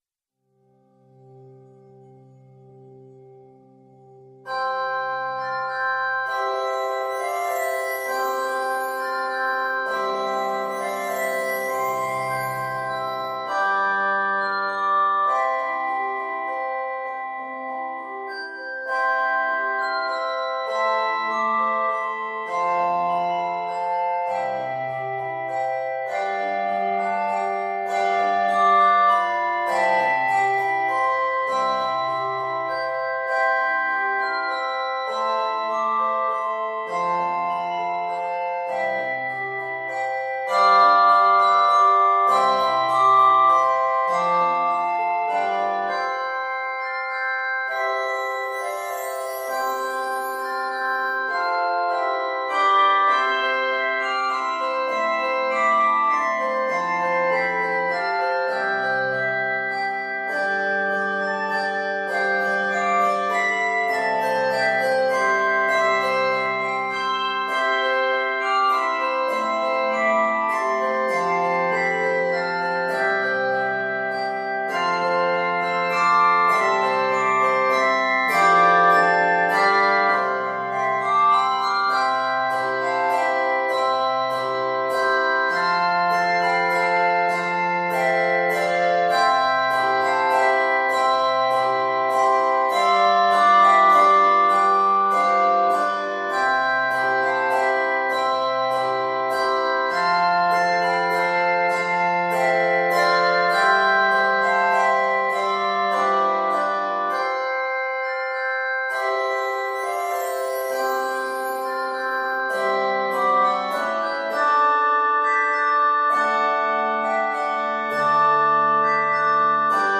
Scored in D Major and B Major, this medley is 117 measures.
Octaves: 3-5